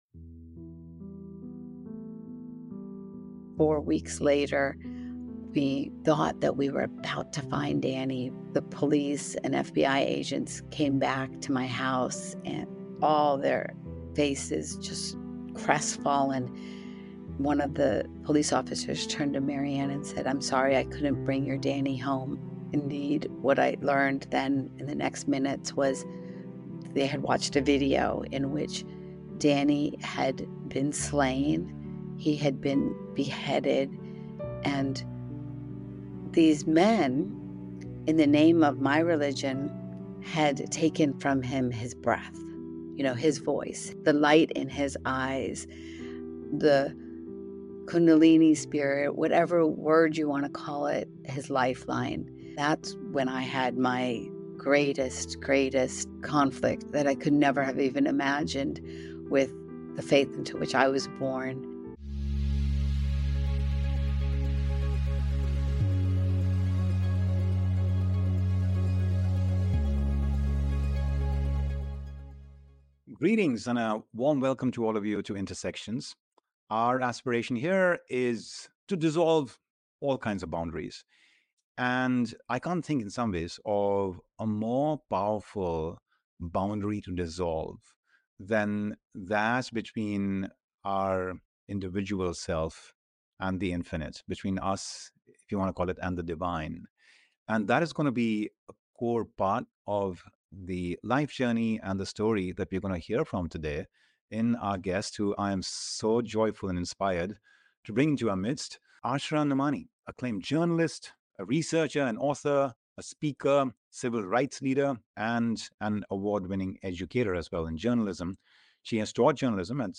Find out from former Wall Street Journal reporter, Asra Nomani, as she recounts her struggle-filled yet inspiring journey of finding hope in the midst of tragedy